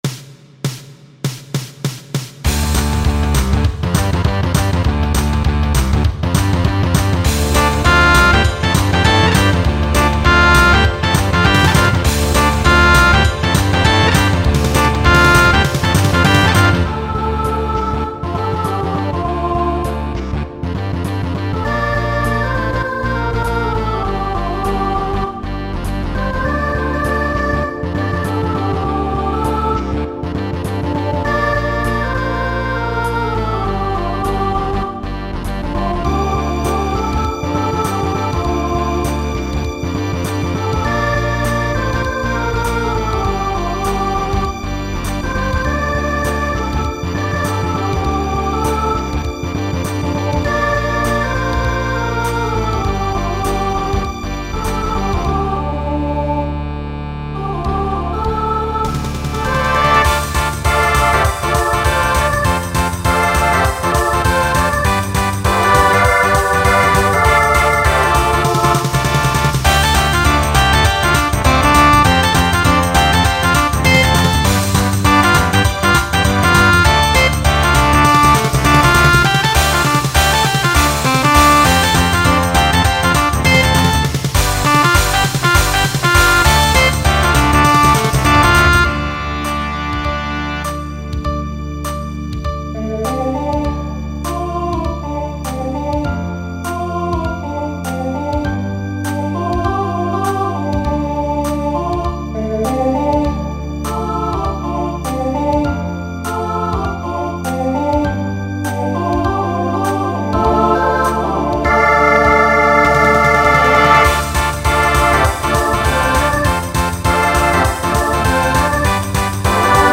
Genre Rock , Swing/Jazz Instrumental combo
Voicing SATB